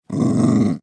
growl3.ogg